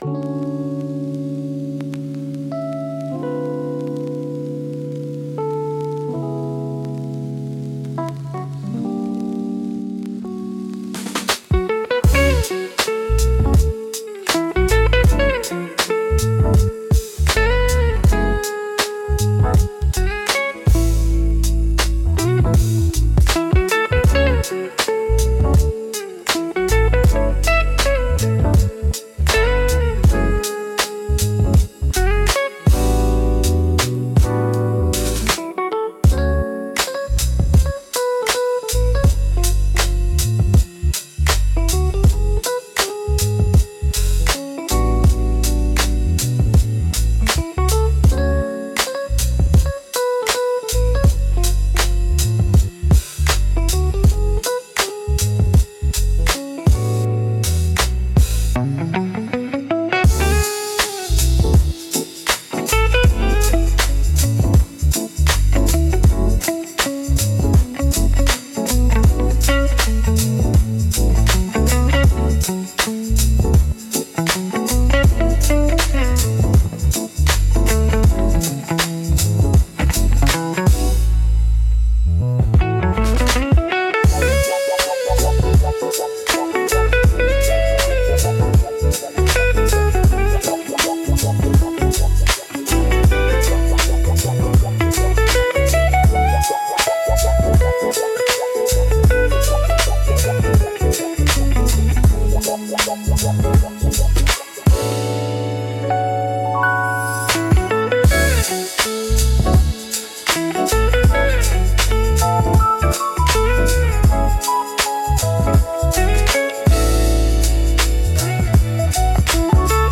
Soft Lo-Fi Groove